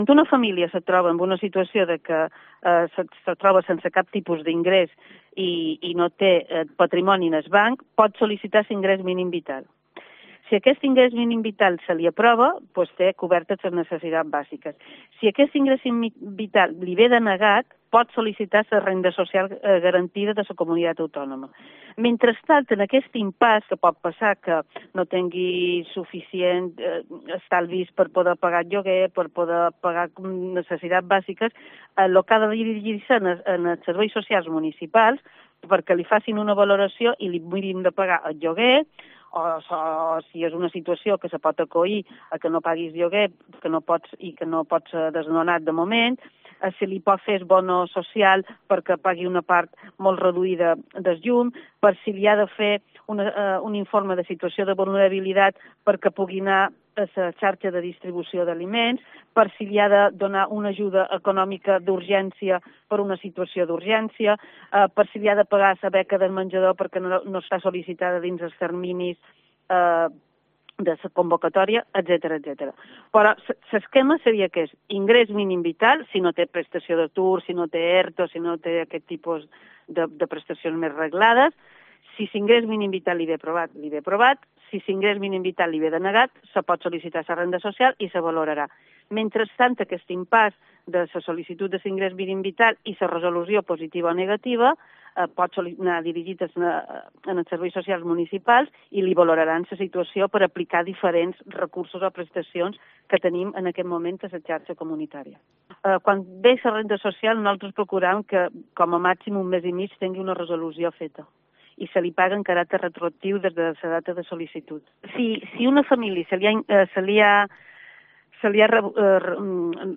La Consellera de Asuntos Sociales explica los pasos para obtener ayuda de la Administración
Fina Santiago, consellera de Asuntos Sociales